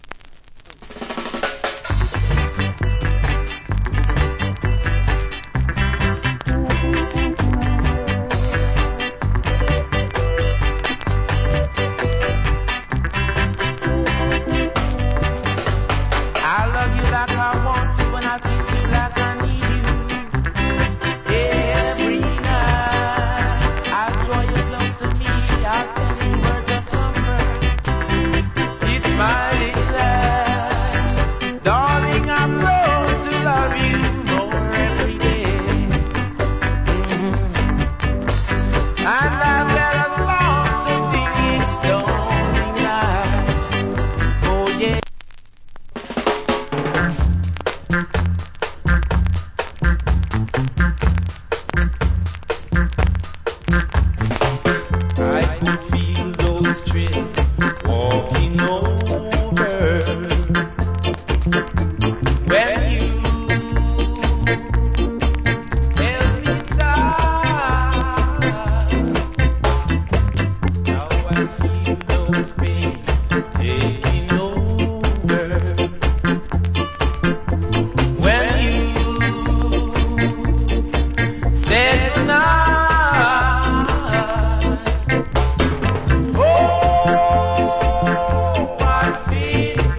Great Vocal.